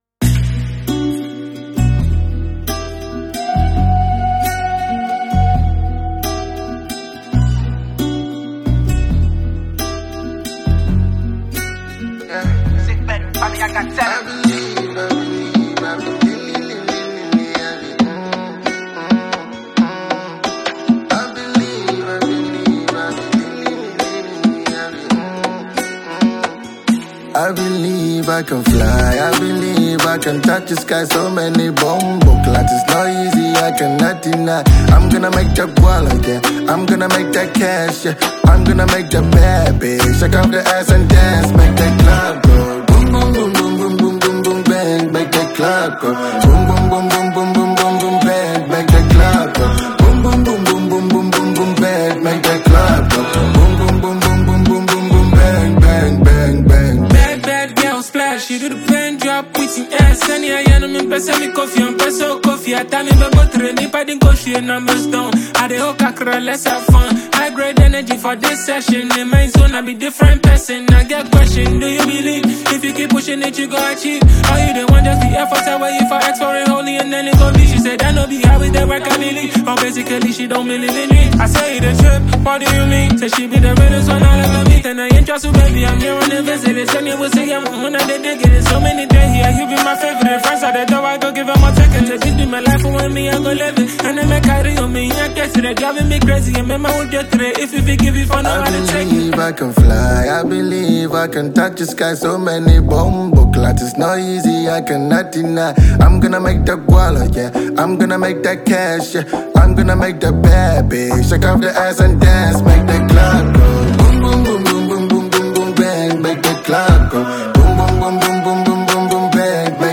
recruited two of the finest top rappers